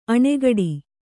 ♪ aṇakāṭa